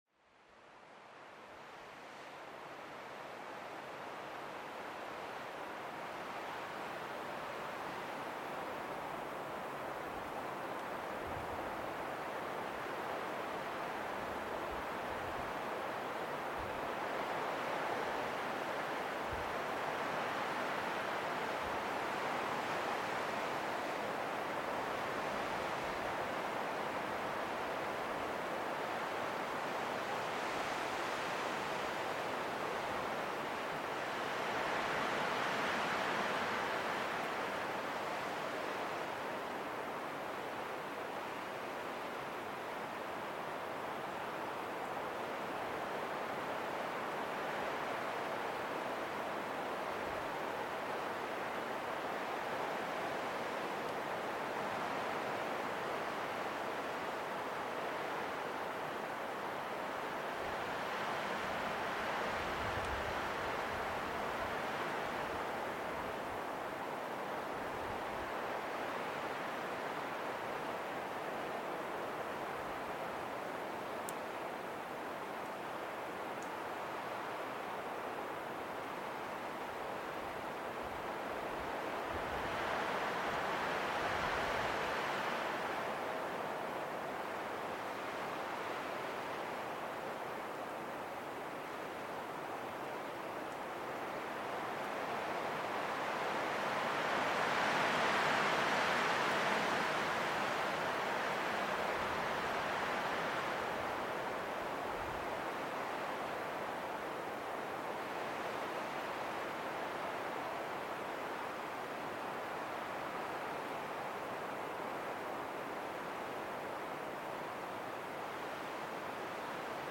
VERGESSENES HEILMITTEL: Kälte-Flüstern durch heilenden Schneefall